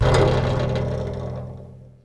Radiator Sound
cartoon